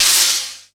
Brush Sn Swish.wav